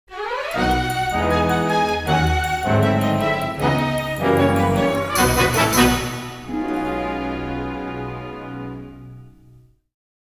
Victory Theme